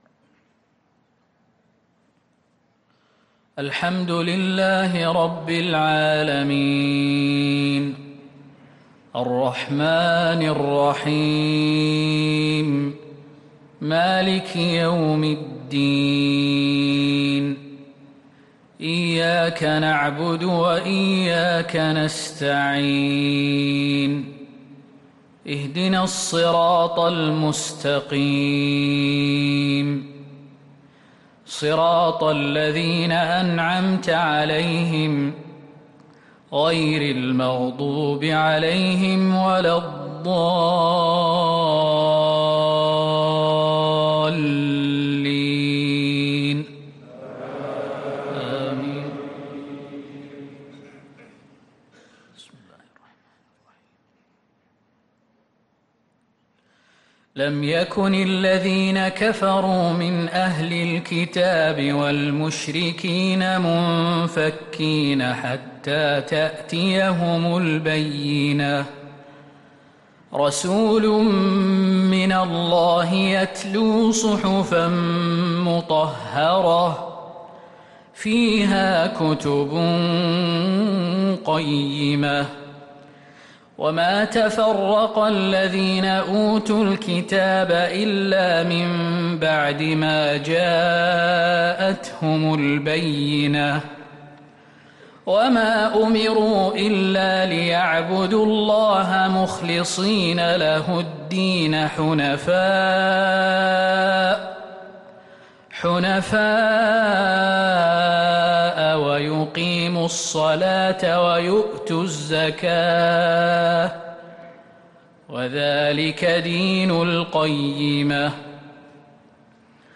عشاء الجمعة 6-2-1444هـ سورتي البينة و الزلزلة | Isha prayer Surat al-Bayyinah & az-Zalzala 2-9-2022 > 1444 🕌 > الفروض - تلاوات الحرمين